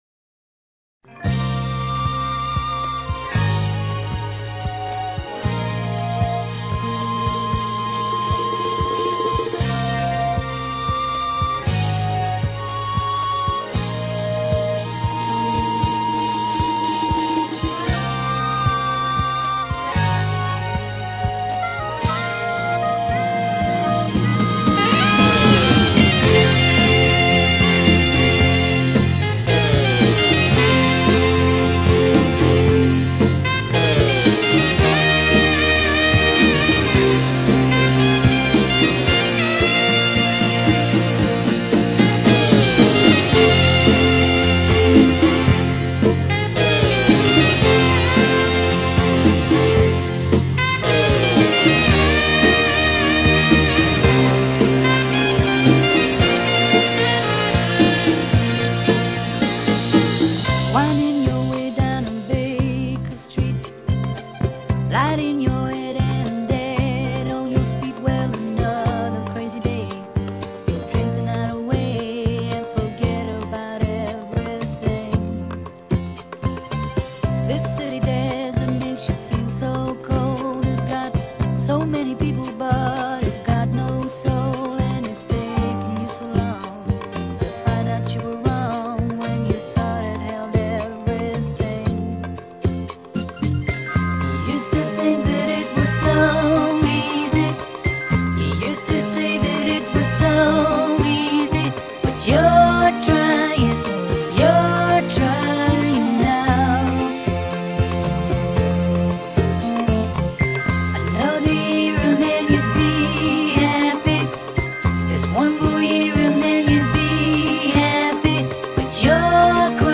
and audio in Cm